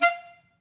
Clarinet
DCClar_stac_F4_v3_rr1_sum.wav